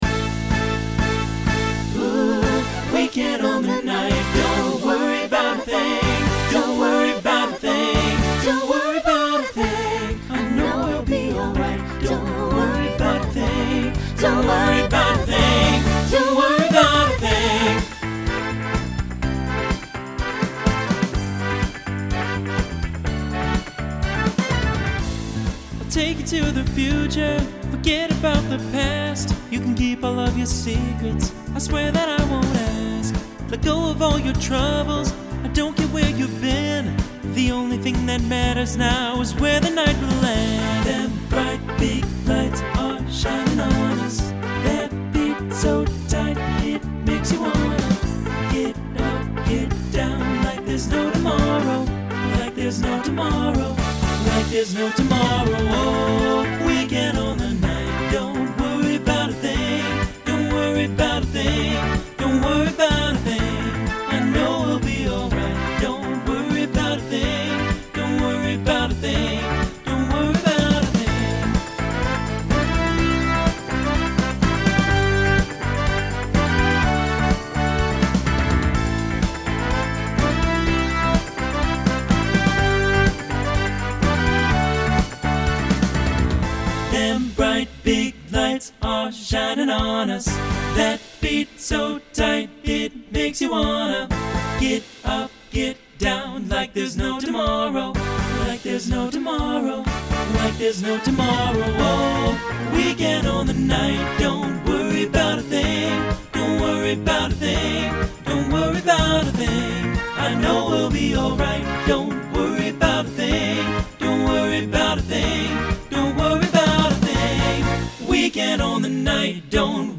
Short SATB vocal statement of the chorus at the beginning.
Genre Pop/Dance